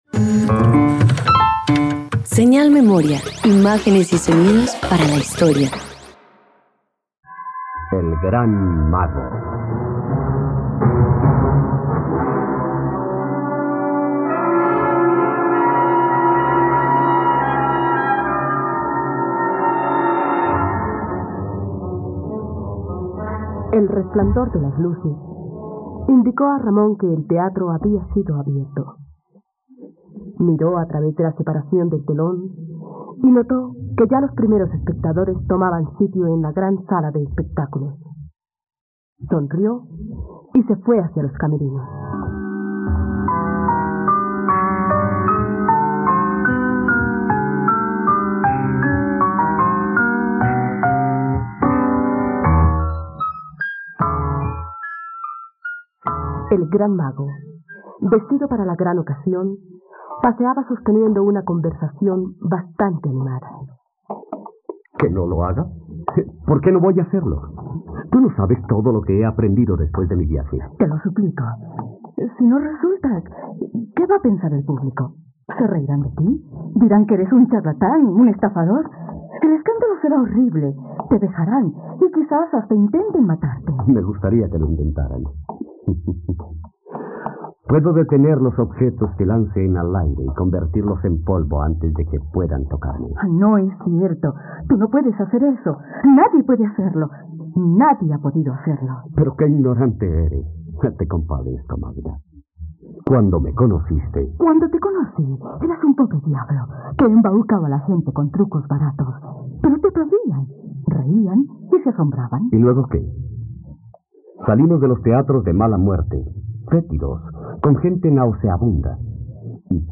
..Radioteatro. Escucha ‘El gran mago’, una obra original de Gonzalo Vera Quintana sobre viajes espaciales.